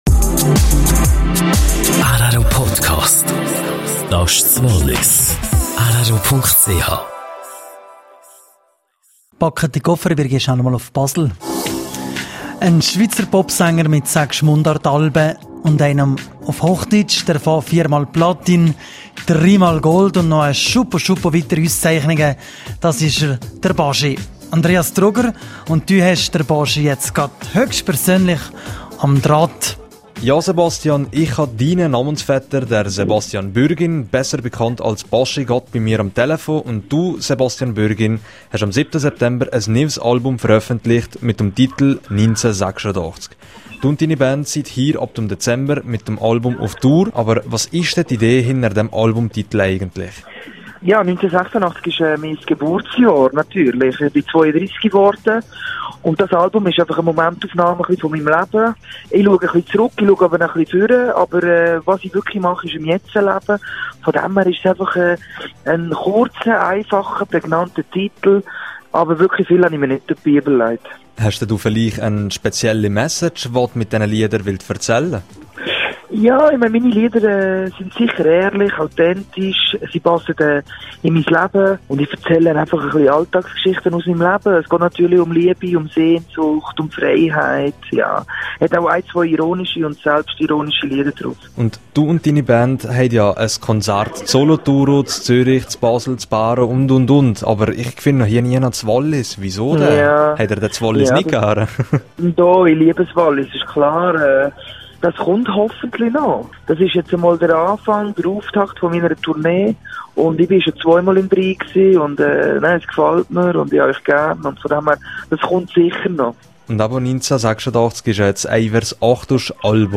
Baschi erzählte rro mehr zu seinem neuen Album "1986".
Ihr könnt auf uns zählen", versprach der Popsänger./vs Interview mit Baschi über sein neues Album "1986".